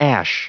Prononciation du mot ash en anglais (fichier audio)
Prononciation du mot : ash